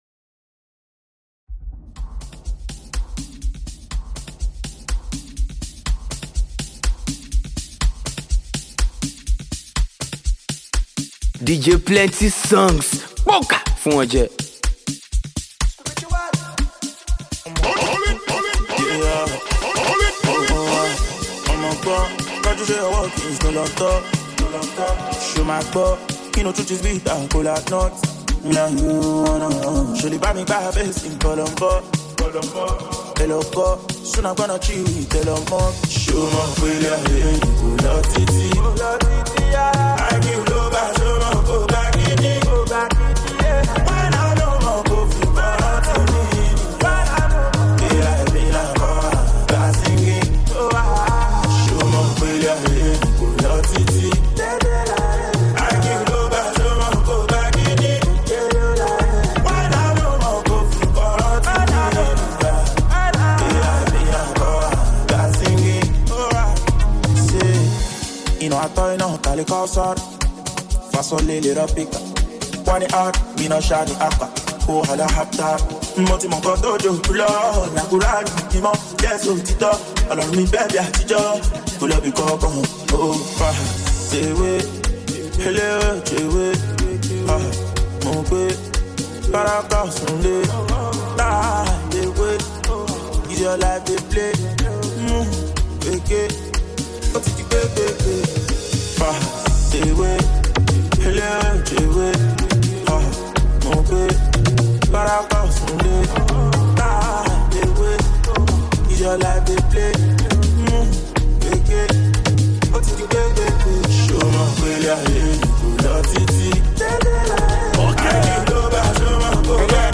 Afrobeat, Afropop, and Naija street vibes